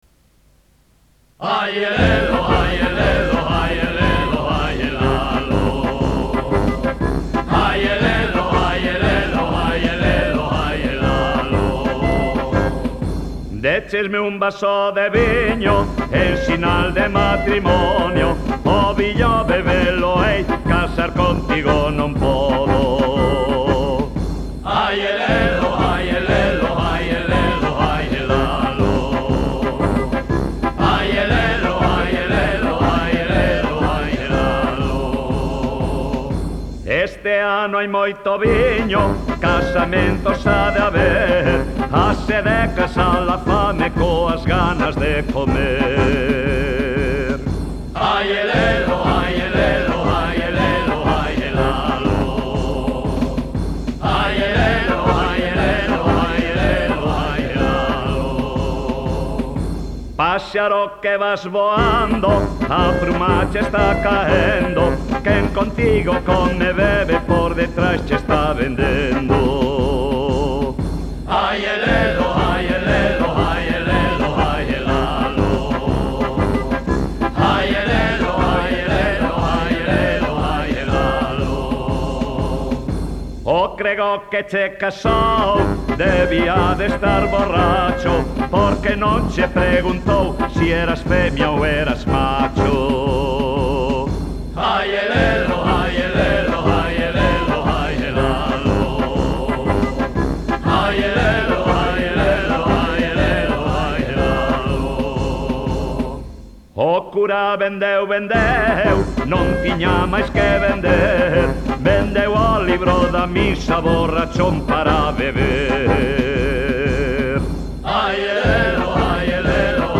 Música:Popular